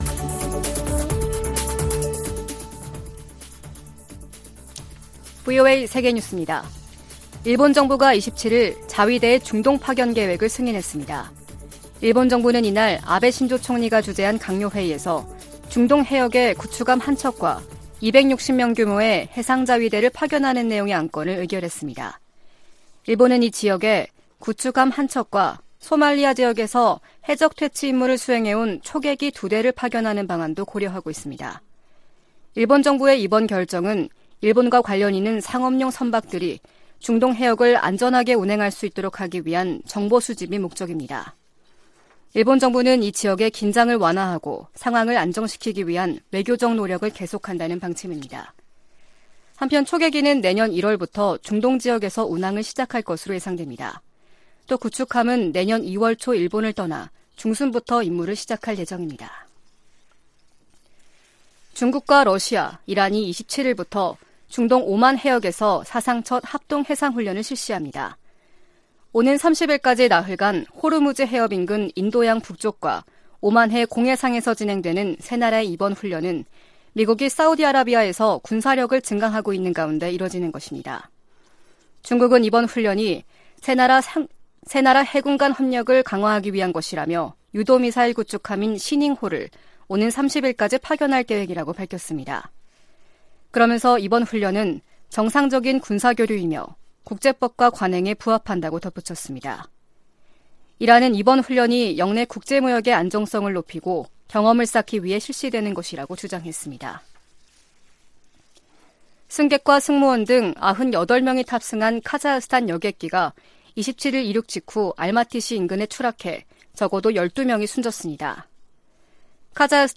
VOA 한국어 아침 뉴스 프로그램 '워싱턴 뉴스 광장' 2018년 12월 28일 방송입니다. 일본의 공영방송인 `NHK'가 북한이 미사일을 발사했다는 오보를 내보냈다가, 이후 잘못된 보도라며 취소하고 사과했습니다. 캐나다 정부는 군용기와 함선을 한반도 주변에 정기적으로 순환배치해 불법 환적 등 북한의 대북 제재 위반 행위를 감시하고 있다고 밝혔습니다.